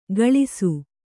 ♪ gaḷisu